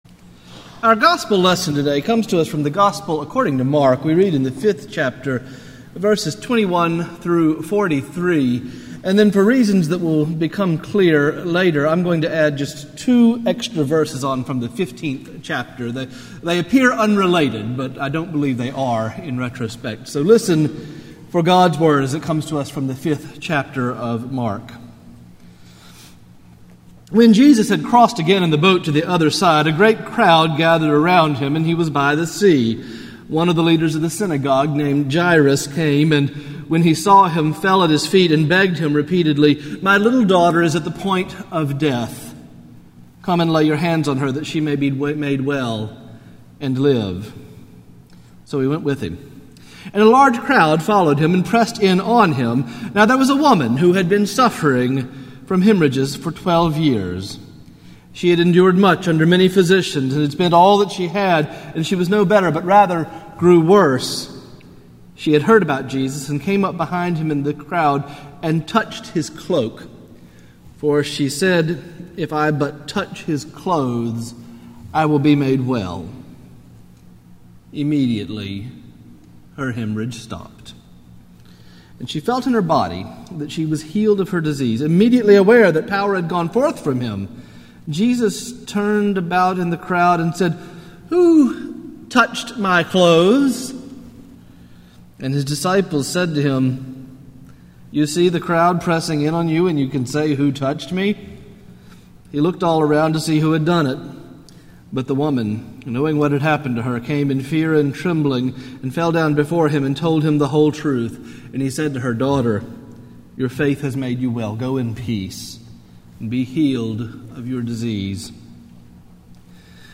Morningside Presbyterian Church - Atlanta, GA: Sermons: The Great Reversal